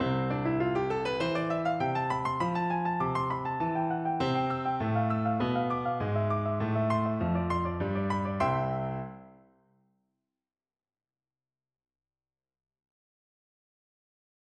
일곱 번째 변주는 강력한 새로운 화음을 도입하여, 주제가 원래 암시했던 단순한 화성을 하행 5도 진행의 연장선으로 대체한다.